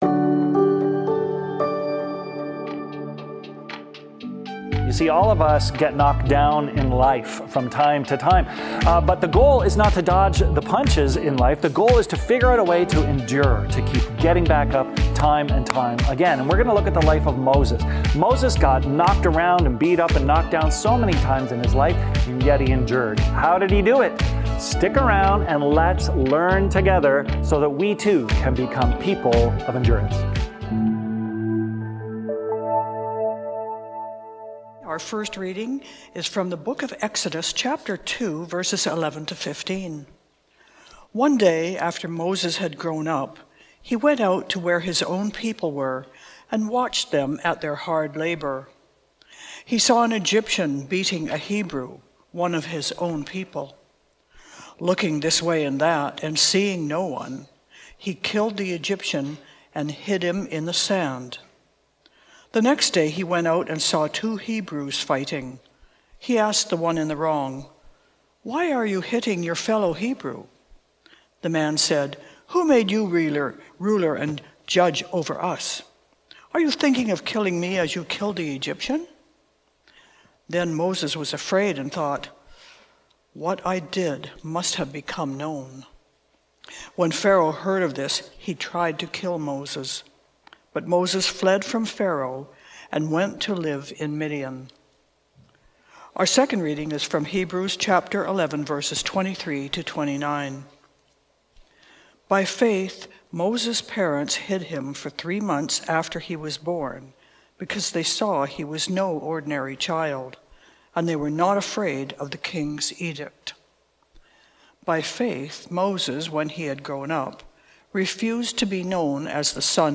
Trinity Streetsville - Moses: A Faith that Endures | Heroes of Faith | Trinity Sermons